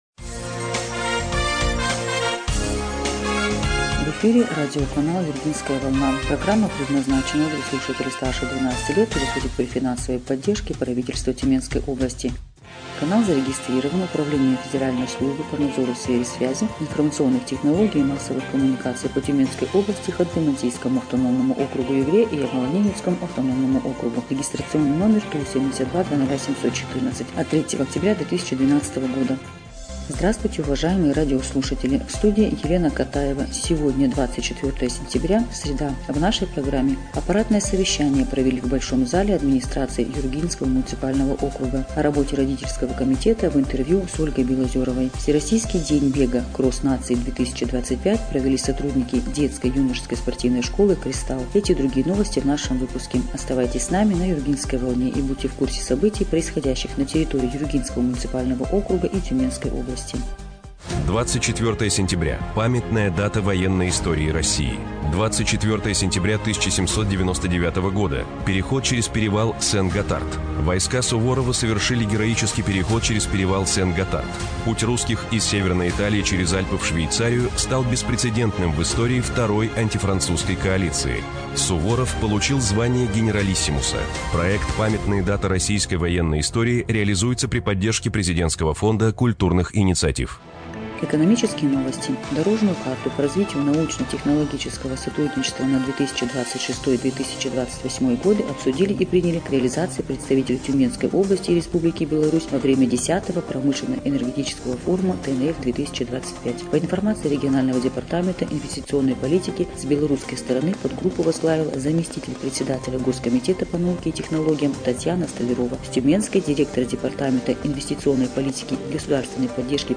Эфир радиопрограммы "Юргинская волна" от 24 сентября 2025 года